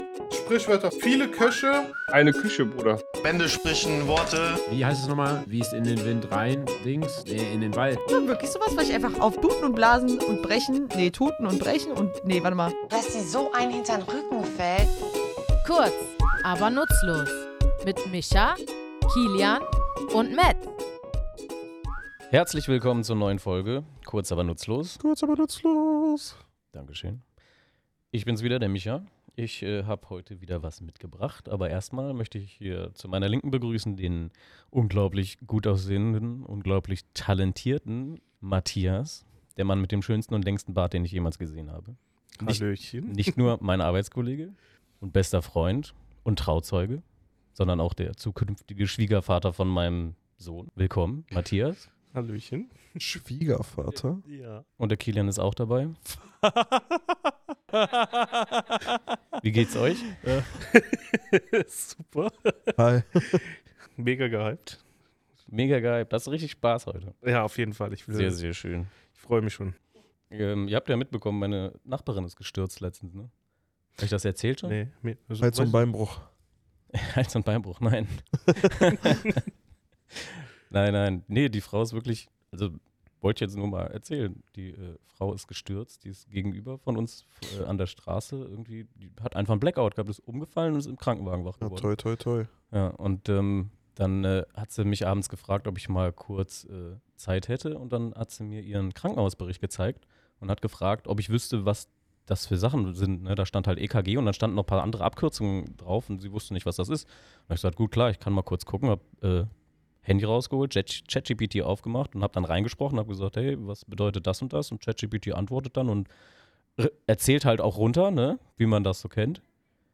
Wir, drei tätowierende Sprachliebhaber, gehen in unserem Tattoostudio der Herkunft und Bedeutung dieser Redewendung auf den Grund.